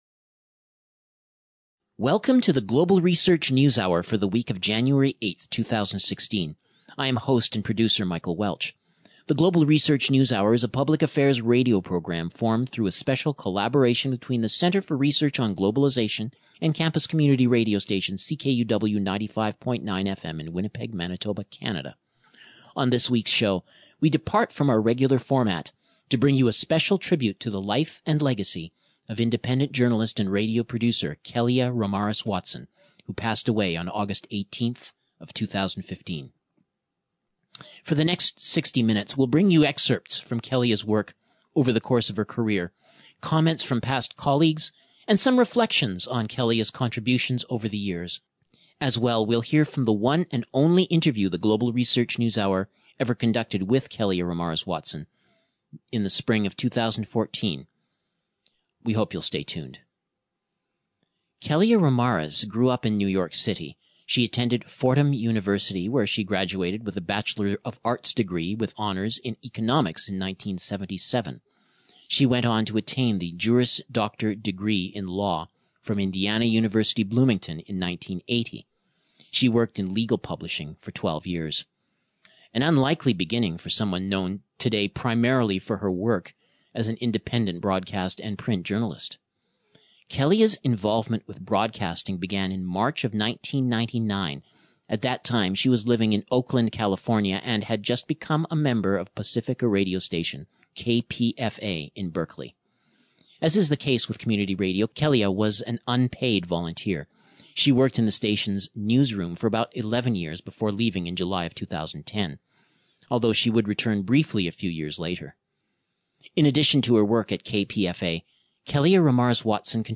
Featuring recordings of the late journalist with commentaries from past colleagues